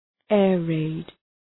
Shkrimi fonetik {‘eərreıd}